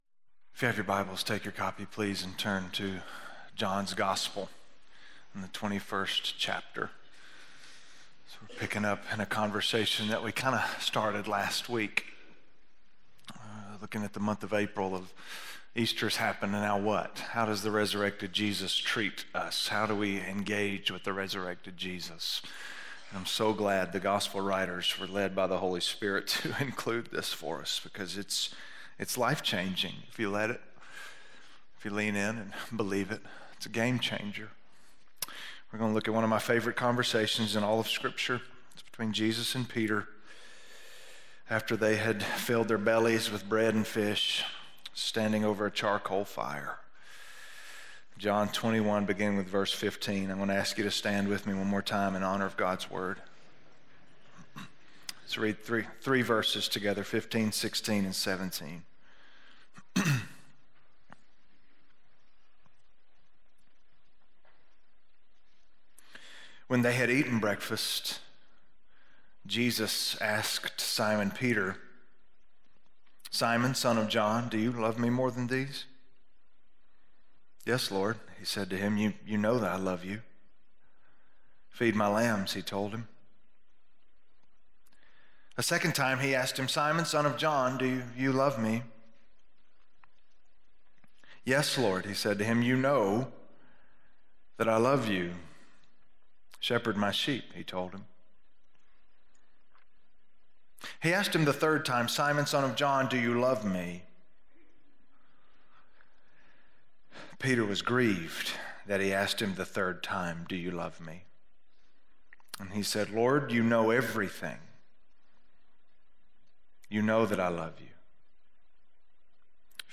Do You Love Me More? - Sermon - West Franklin